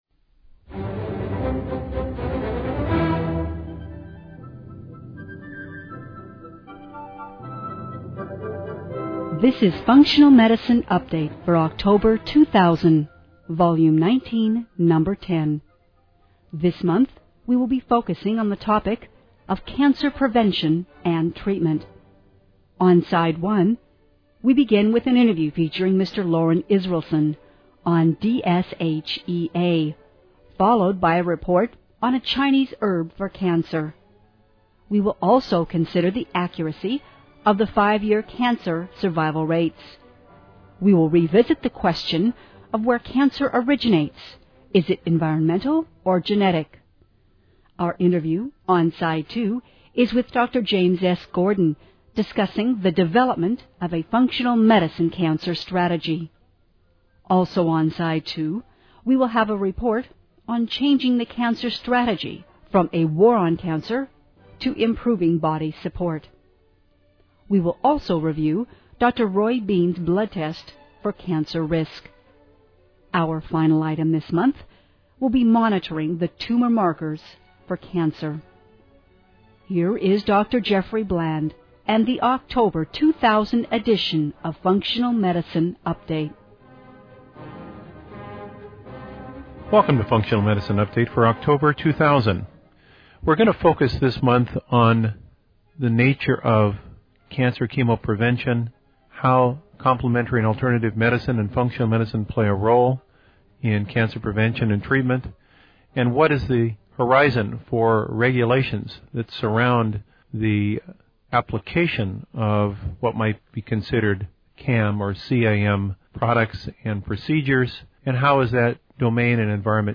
This month we will have a special interview with an Expert of the Month, along with our traditional Clinician of the Month interview. This month’s focus is on the role of complementary and alternative medicine in cancer prevention and treatment, and the prospects for regulations on the application of what might be considered complementary and alternative medicine (CAM) products and procedures. We will examine the effect on that domain of passage of the 1994 Dietary Supplement and Health Education Act (DSHEA).